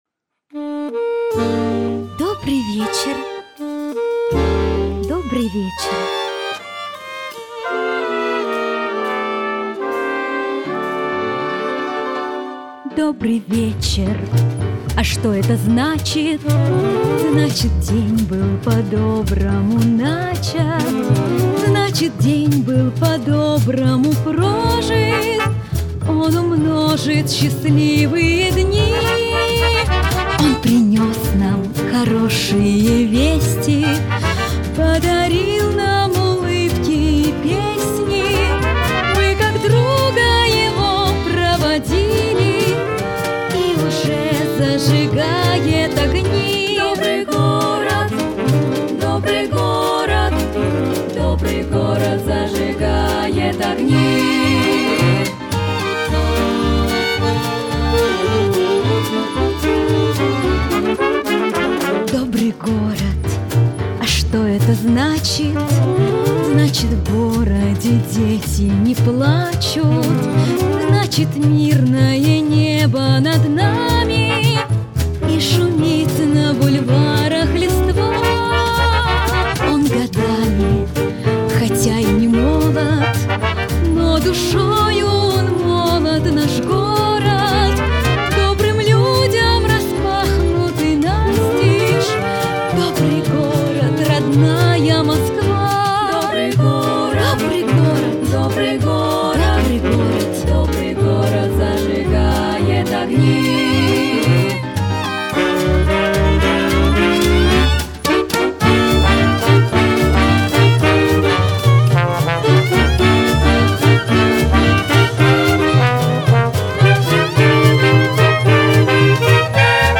Аудиозаписи оркестра